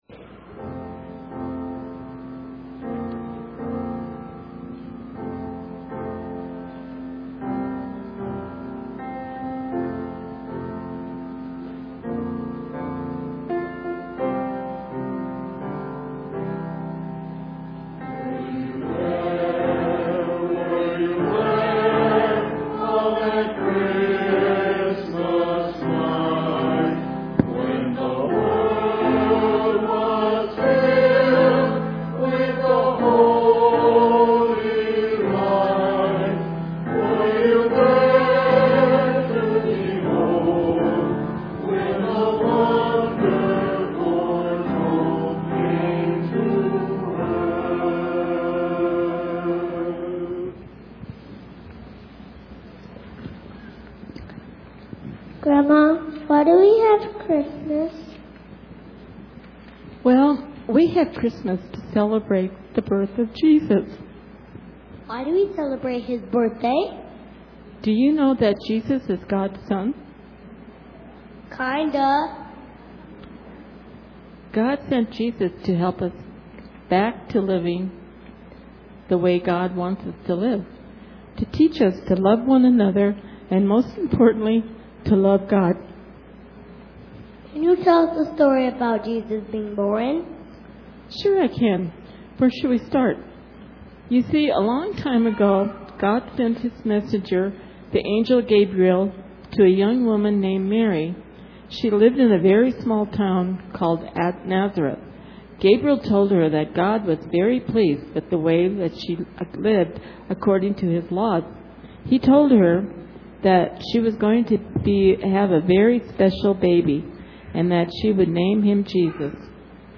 Chancel Choir Youth Group and Sunday School Class presents 2012 Christmas Cantata
Piano accompaniment